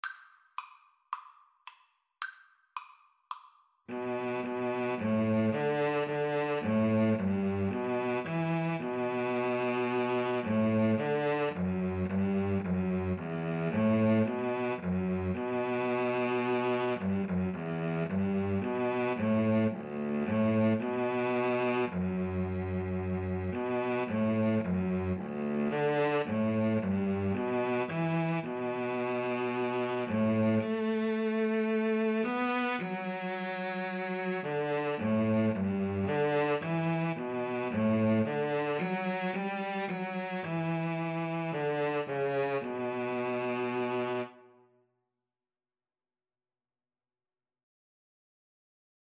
B minor (Sounding Pitch) (View more B minor Music for Oboe-Cello Duet )
Gently Flowing = c. 110
4/4 (View more 4/4 Music)
Traditional (View more Traditional Oboe-Cello Duet Music)